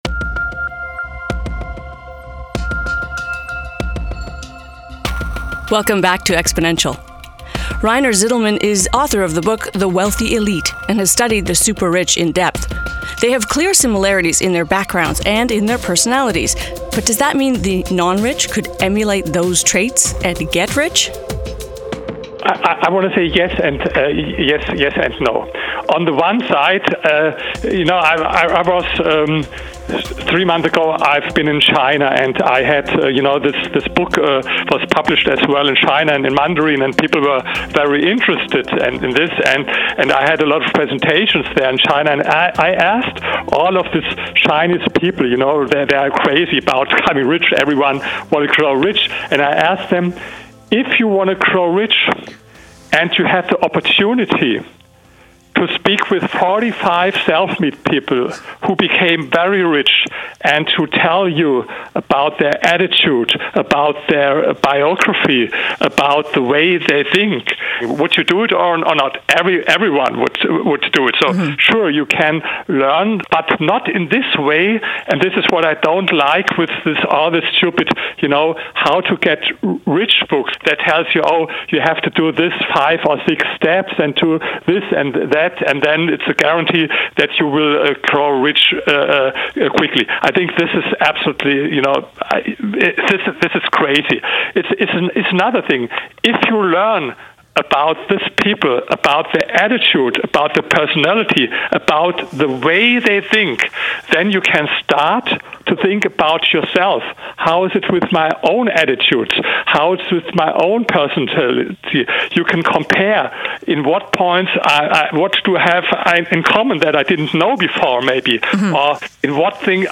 Radio-interview